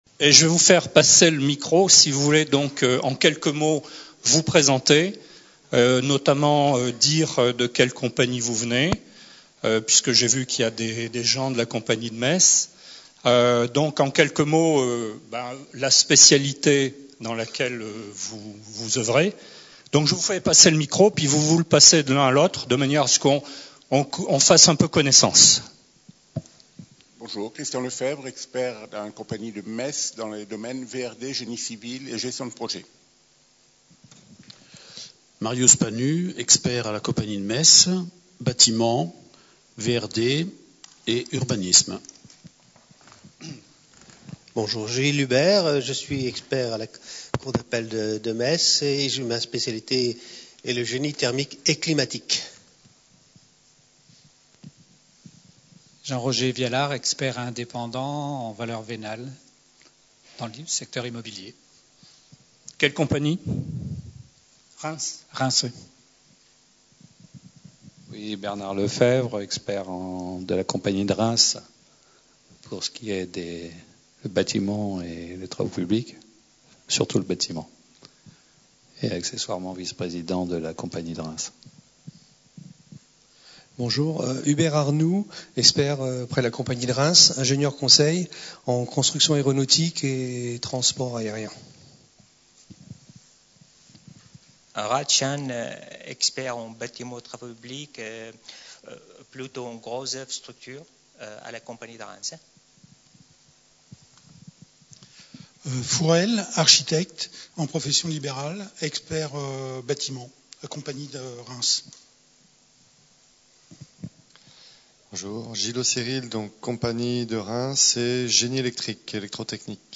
Conférence enregistrée lors du 7ème Colloque de la Compagnie des Experts de Reims.